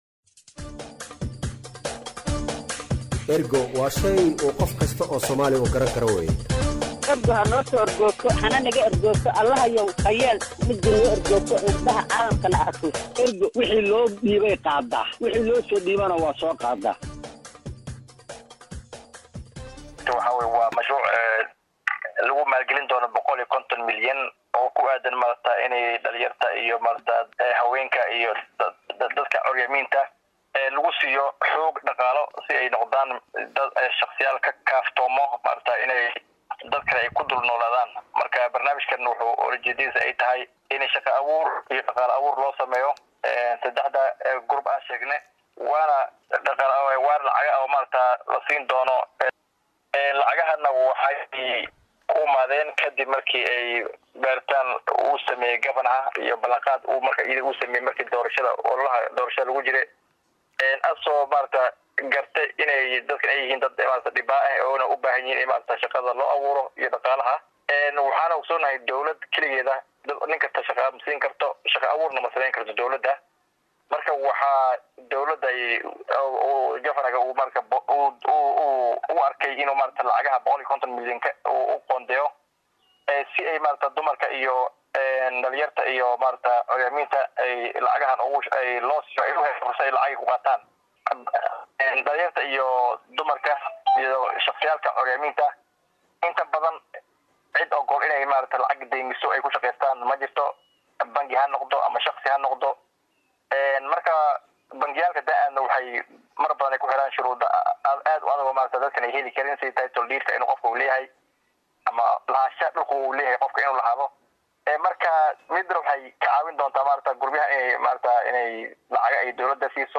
Wasiirka Ganacsiga iyo Dalxiiska ee ismaamulka Gaarisa Caddow Kaliil Jubad ayaa mashruuca uga warramay weriyaha Raadiyo Ergo